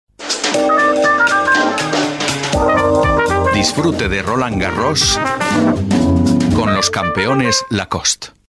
Sprechprobe: Werbung (Muttersprache):
spanish voice over artist